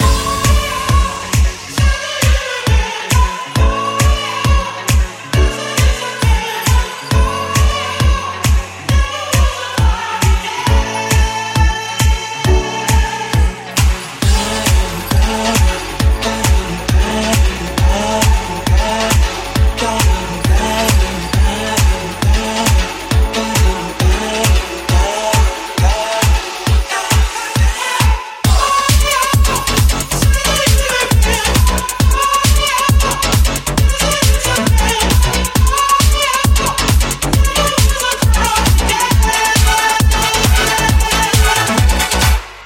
hits remixed
Genere: club, dance, edm, electro, house, successi, remix